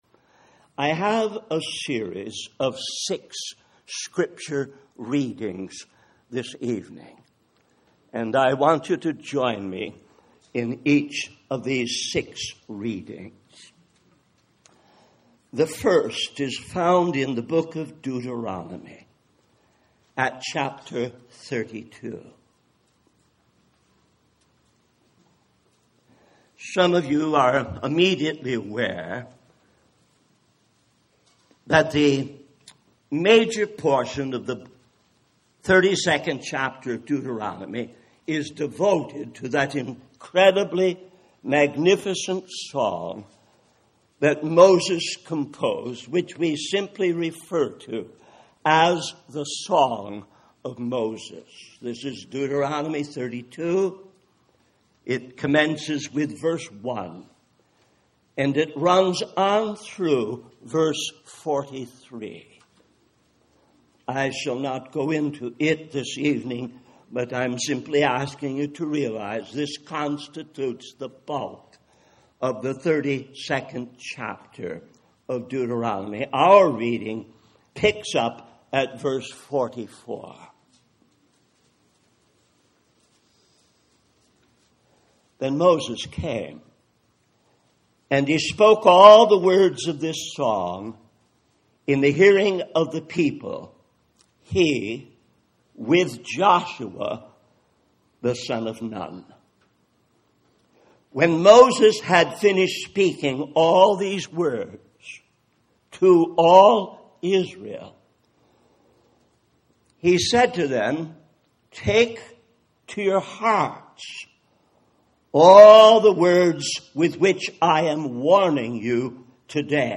In this sermon, the speaker shares a personal story about his father's radical conversion to Christianity and his ministry in the county poorhouse. The speaker then recounts his own experience of being asked to preach at a young age and how his world expanded as he shared the Word of God in different settings. He emphasizes the importance of recognizing sin as both a passport to hell and a thief of God's glory, which he believes is often overlooked in the American evangelical movement.